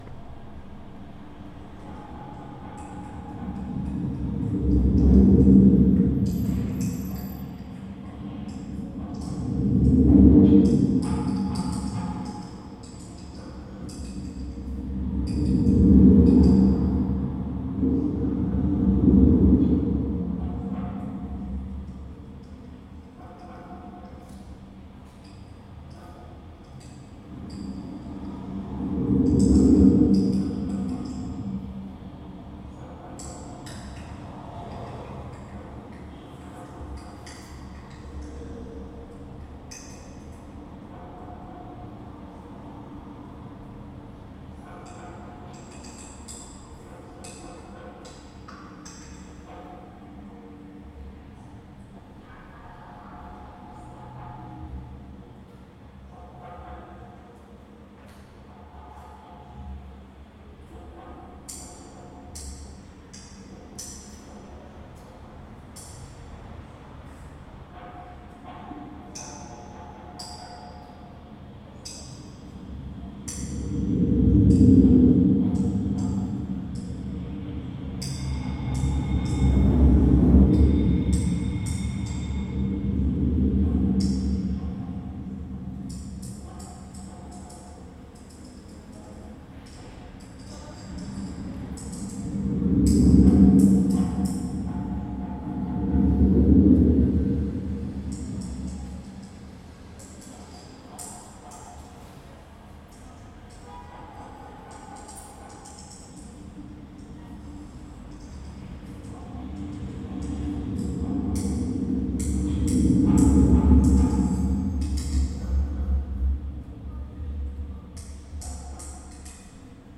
the city as object, the interobjective, being rhythmed by place, emplaced, step by step, across surfaces and into depths, the imaginary city. ( installation recording )